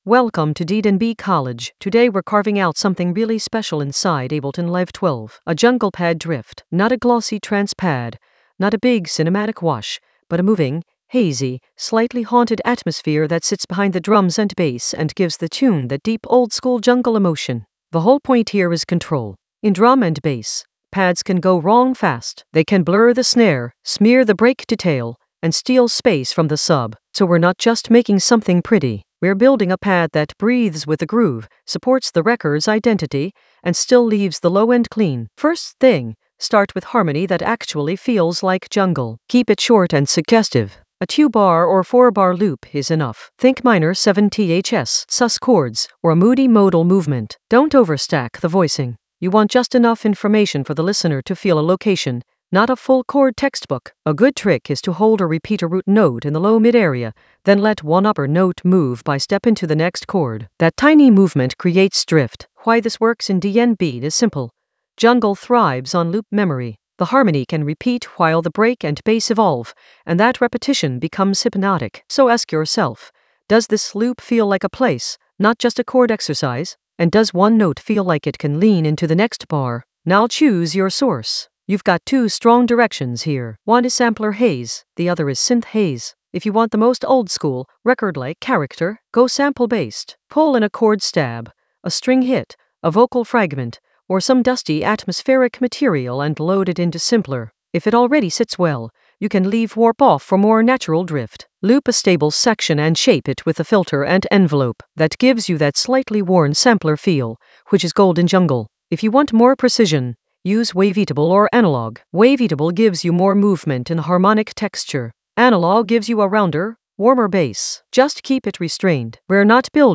An AI-generated advanced Ableton lesson focused on Carve a jungle pad drift in Ableton Live 12 for jungle oldskool DnB vibes in the Vocals area of drum and bass production.
Narrated lesson audio
The voice track includes the tutorial plus extra teacher commentary.